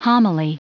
Prononciation du mot homily en anglais (fichier audio)
Prononciation du mot : homily